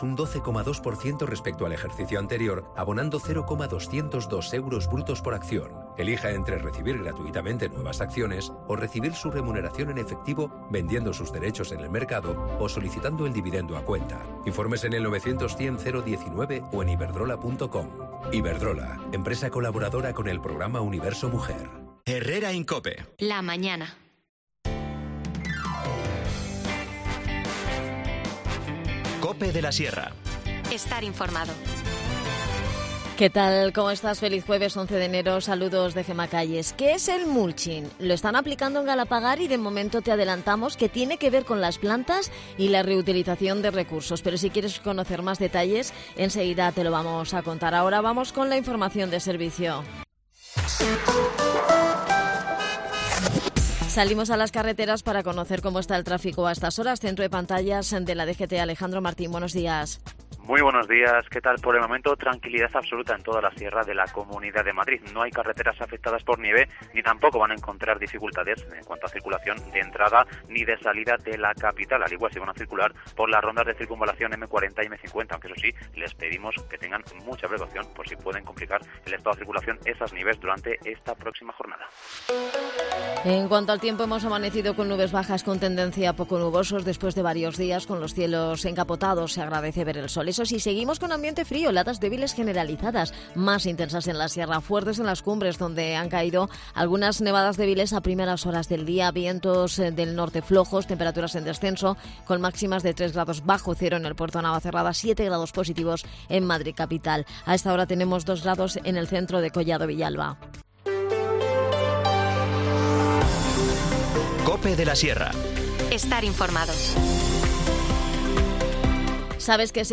De este asunto nos ha hablado Joel Dalda, concejal de Deportes y Juventud.
Las desconexiones locales son espacios de 10 minutos de duración que se emiten en COPE, de lunes a viernes.